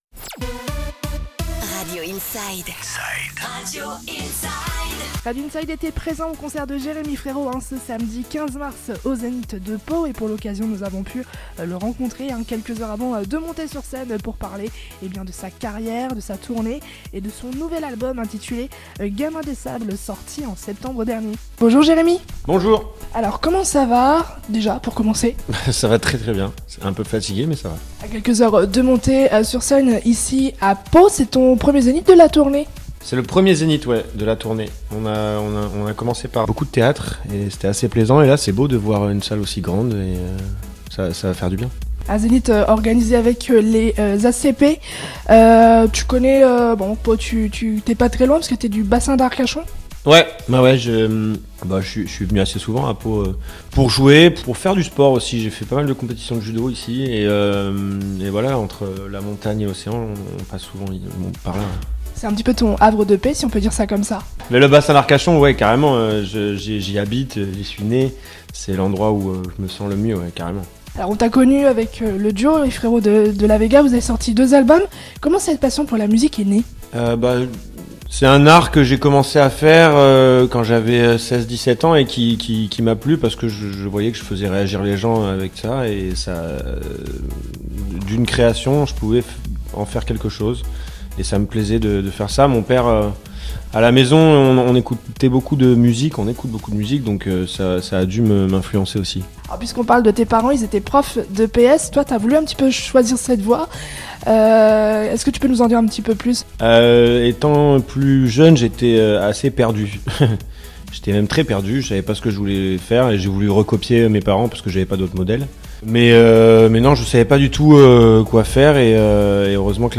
Interview de Jérémy Frerot "Gamin des sables" sur Radio Inside